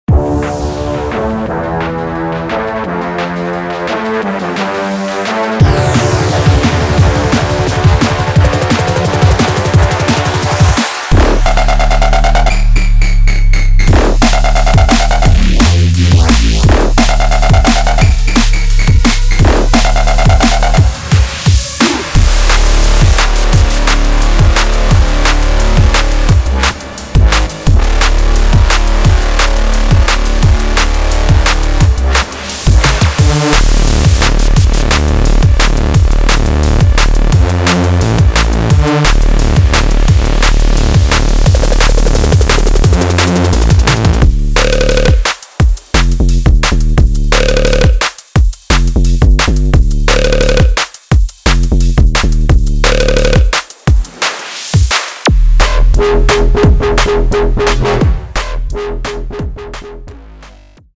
Drum And Bass